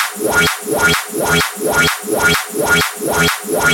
VEH1 Fx Loops 128 BPM
VEH1 FX Loop - 08.wav